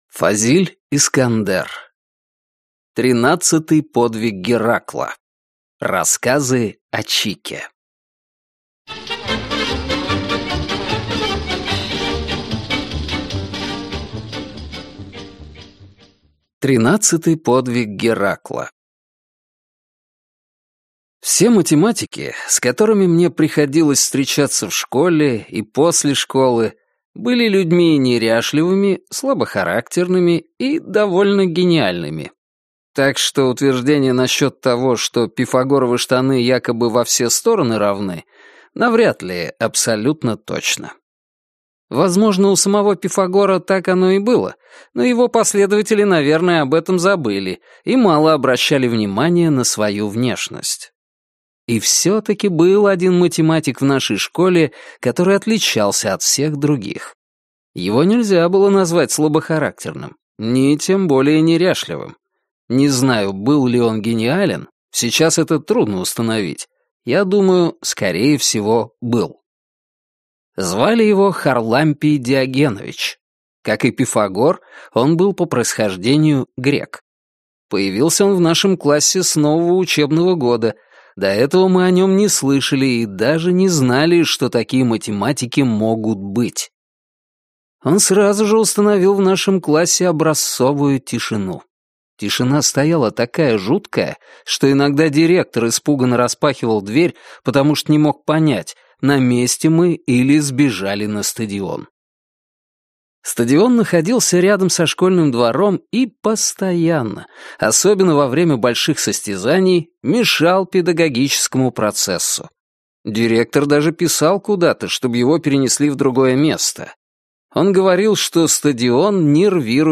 Аудиокнига Тринадцатый подвиг Геракла. Рассказы о Чике (сборник) | Библиотека аудиокниг